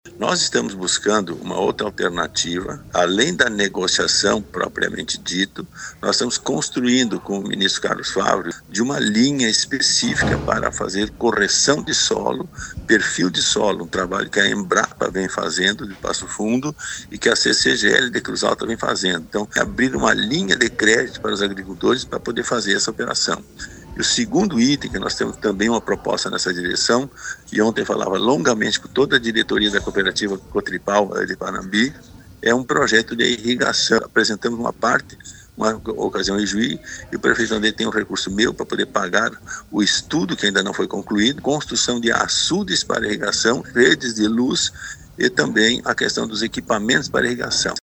Além dos encaminhamentos mais imediatos de apoio aos agricultores do Rio Grande do Sul, no caso, prorrogação de dívidas de até três anos para Pronaf, Pronamp e demais, além de um ano de prazo para investimentos, visto descapitalização pelas últimas frustrações de safras, outras iniciativas estão em debate. O senador pelo PP, Luis Carlos Heinze, disse para a RPI que está em organização, com o Ministro da Agricultura, Carlos Fávaro, uma linha de crédito para correção de solo.